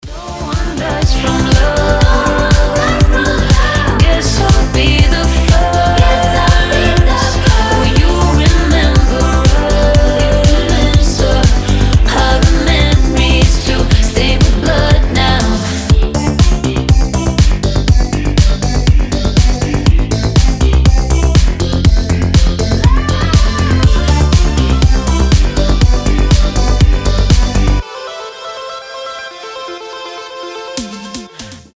Synth Pop
женский голос
Electropop